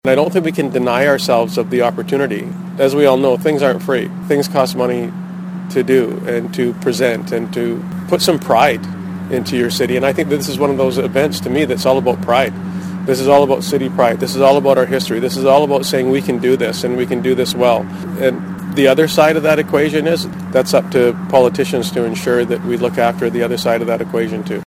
Councillor Bill Bestwick had this to say to critics who say the money could be better spent on addressing issues like poverty…..